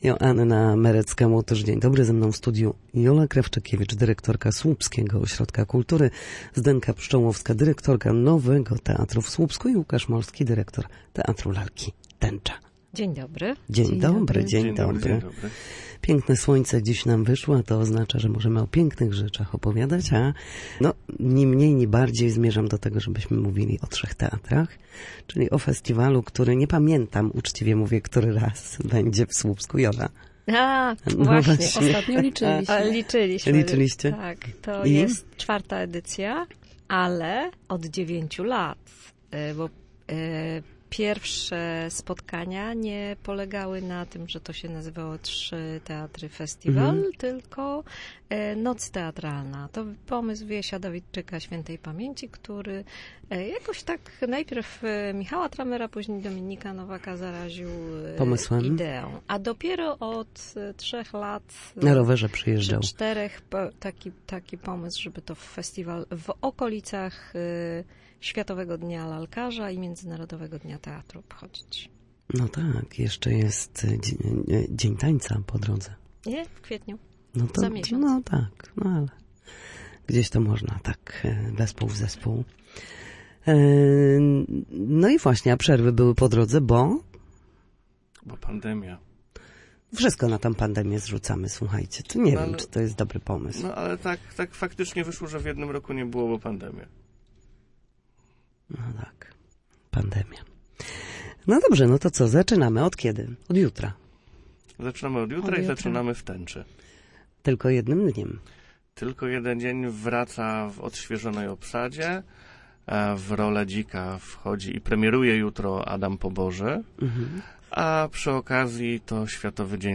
Gośćmi Studia Słupsk byli